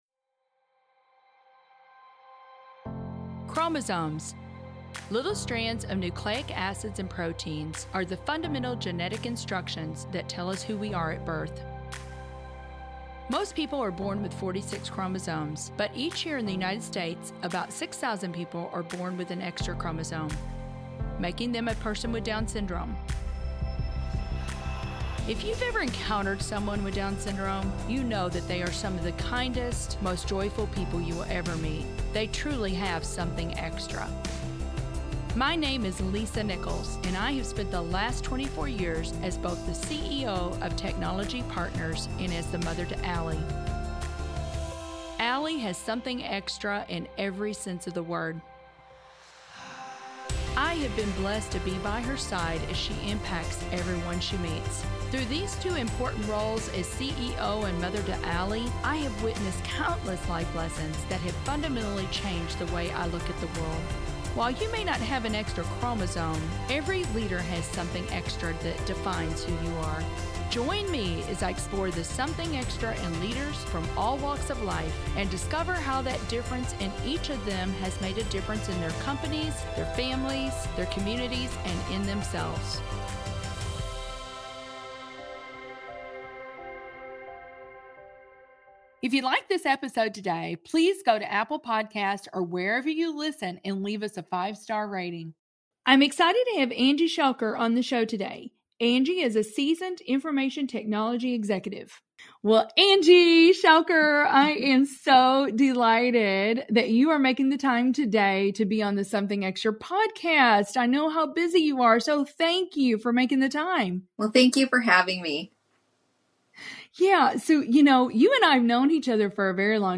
Without them, she says, we can miss out on opportunities for growth. Whether you consider yourself a technologist or a stranger to the field, we encourage you to check out this conversation about taking the leap.